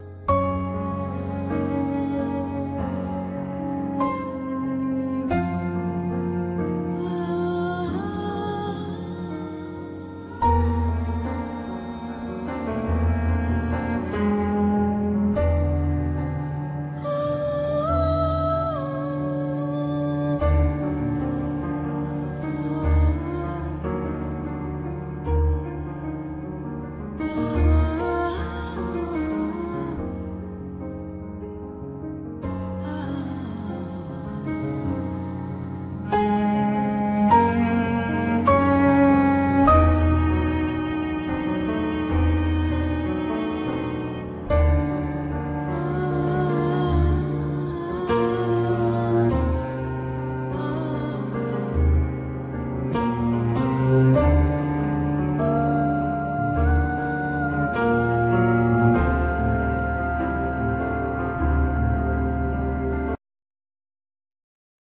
Drums, Percussions
Voice
Piano
Cello